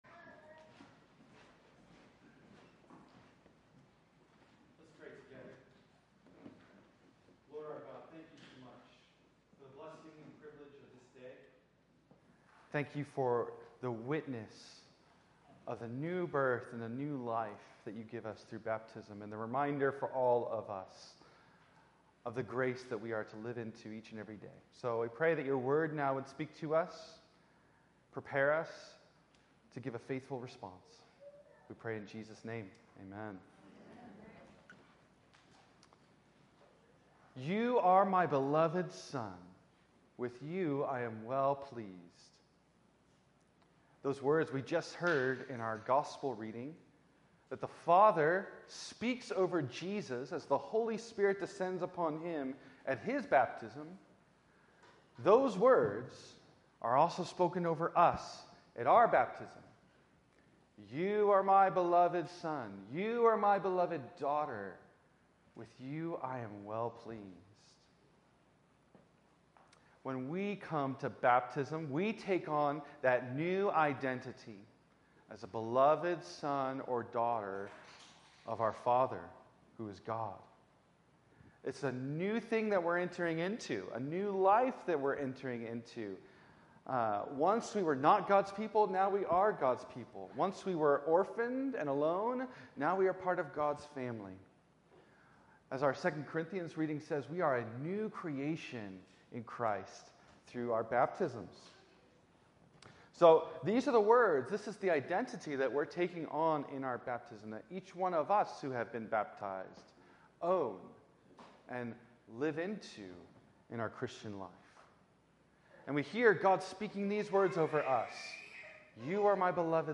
Promises (A Baptism Sermon)